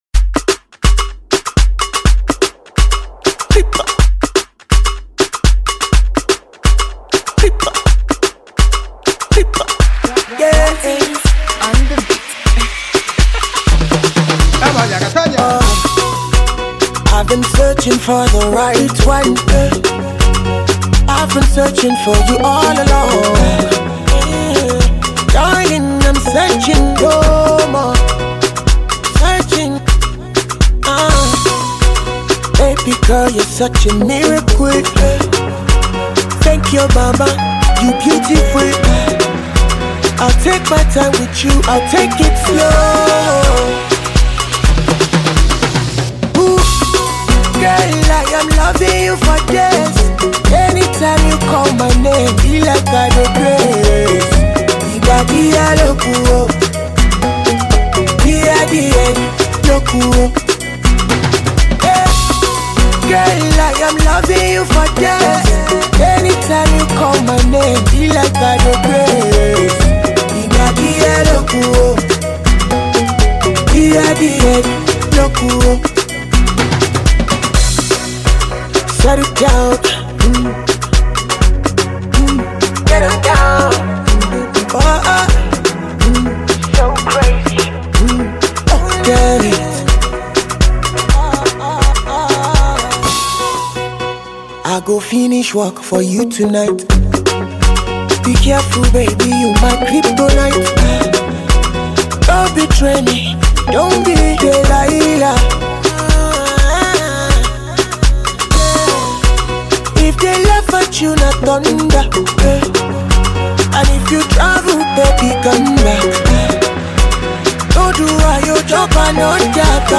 Afro-pop
groovy tune that will likely keep you on your feet dancing